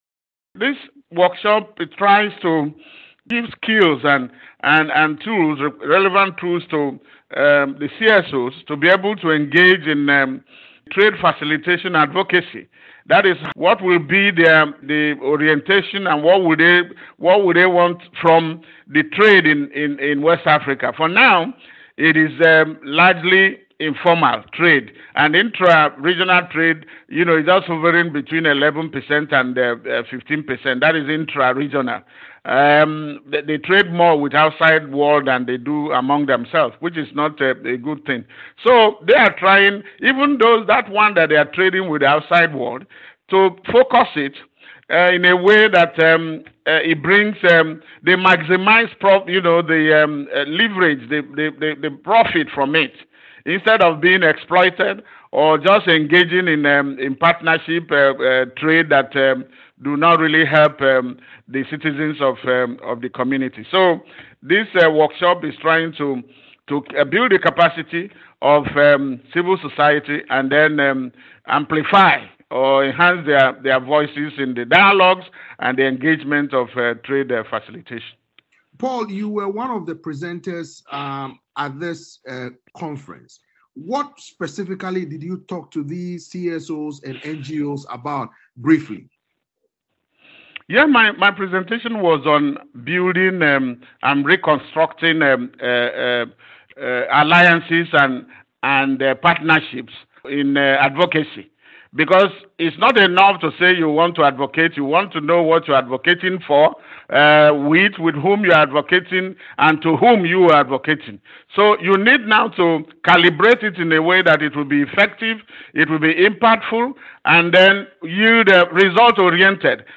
spoke to analyst